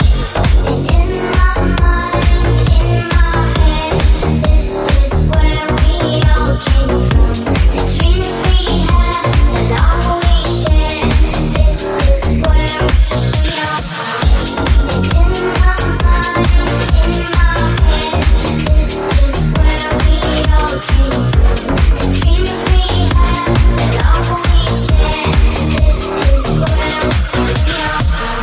deep house
Genere: house, deep house, remix